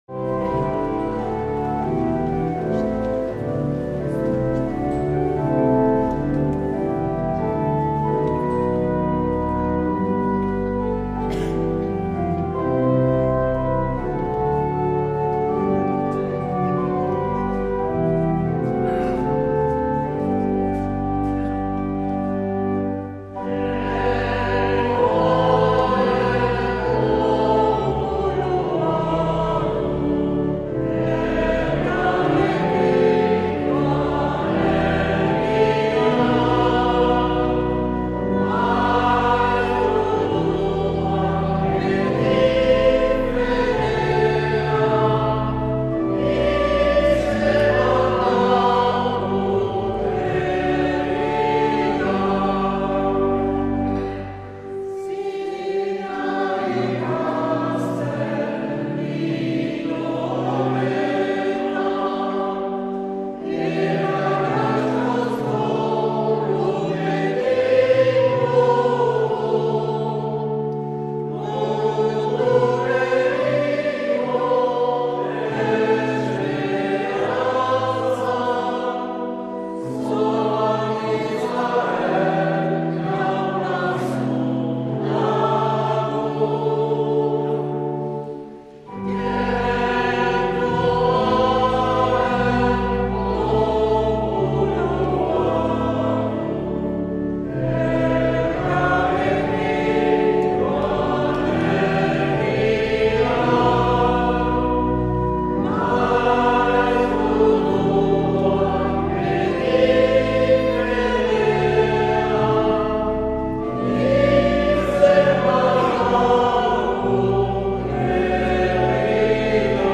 Accueil \ Emissions \ Vie de l’Eglise \ Célébrer \ Igandetako Mezak Euskal irratietan \ 2022-02-27 Urteko 8.